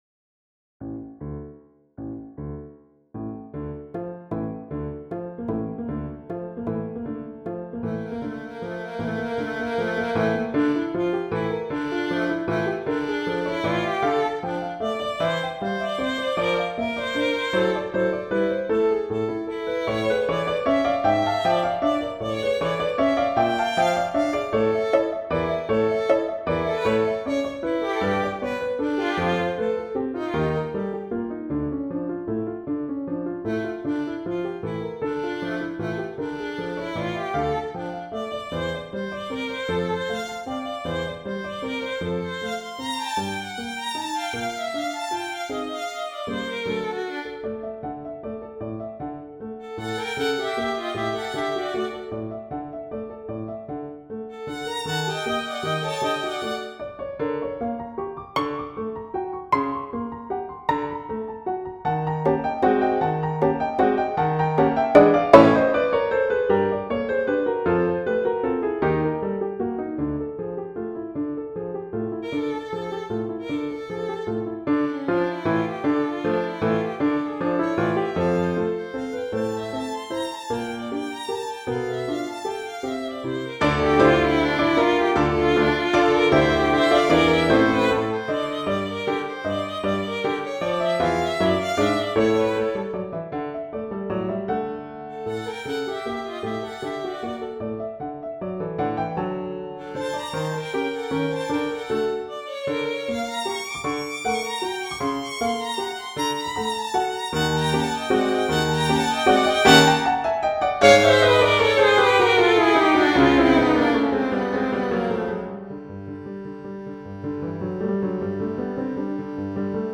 violin/piano arrangement